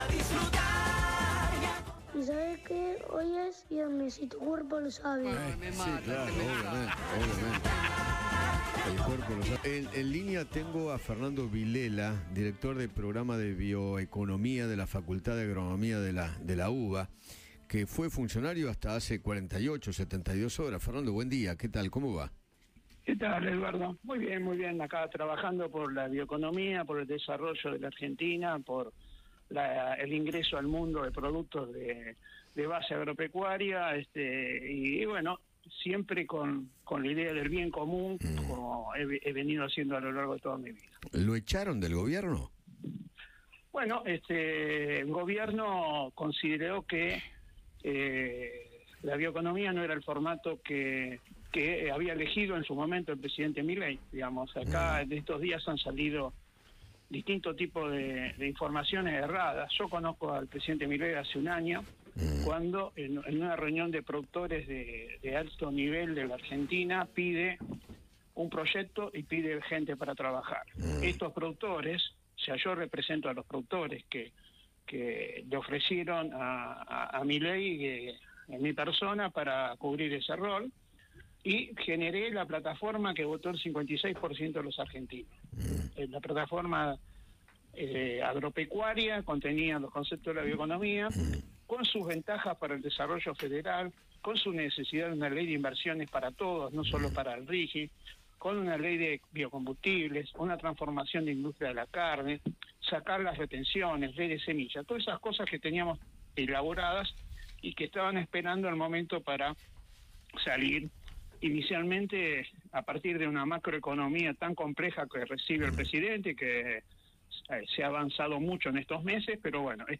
Fernando Villela, ingeniero agrónomo, habló con Eduardo Feinmann sobre su gestión como secretario de Agricultura y negó que haya querido irse del Gobierno.